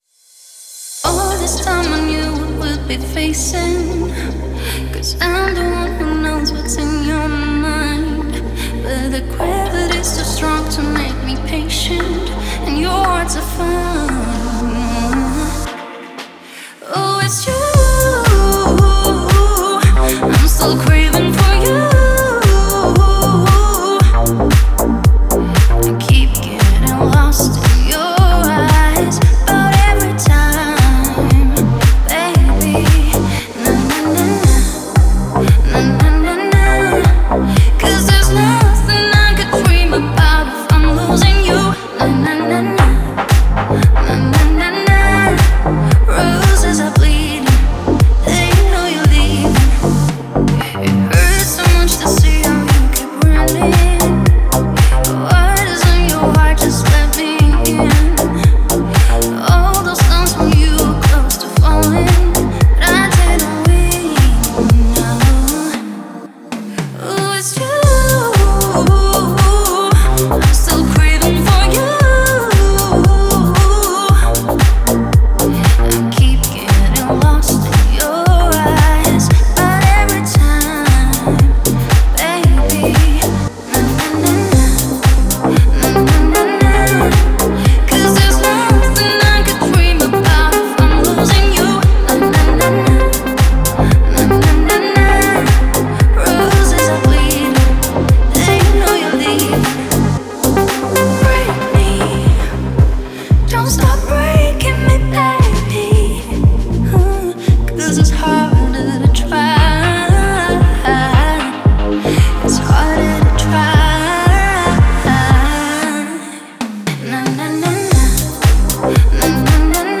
это захватывающий трек в жанре EDM